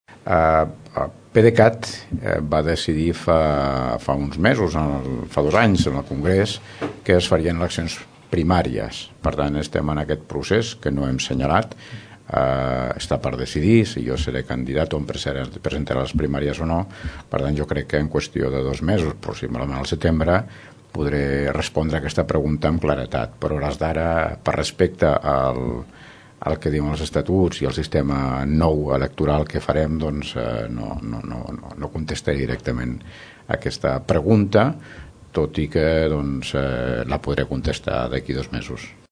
En aquest sentit, Joan Carles Garcia explica que encara no s’ha plantejat, “per respecte als estatuts”, qui es presentarà a les primàries. Ho afirma l’alcalde de Tordera.